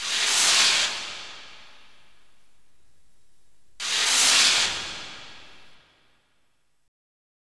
64BELLS-HH-R.wav